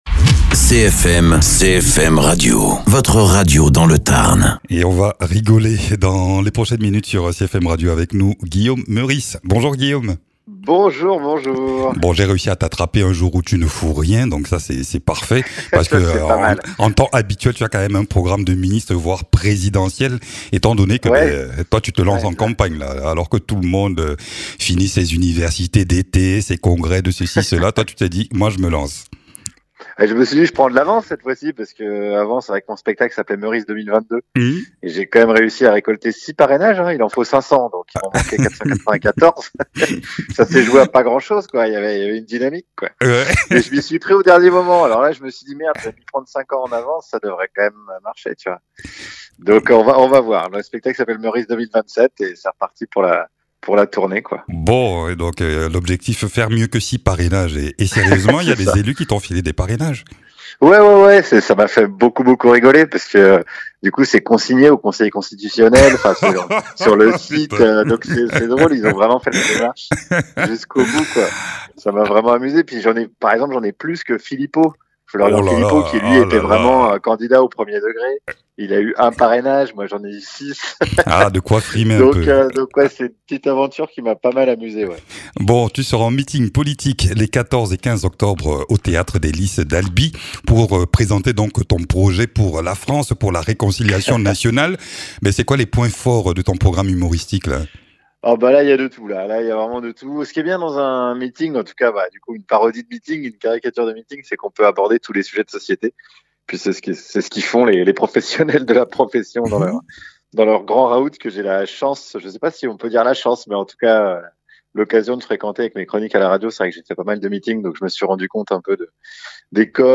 Interviews
Invité(s) : Guillaume Meurice, humoriste et chroniqueur radio ainsi qu’homme politique sur scène...